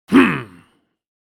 Hmph Sound Effect
Male “Hmph” sound effect expressing annoyance, disapproval, or frustration.
Human sounds.
Hmph-sound-effect.mp3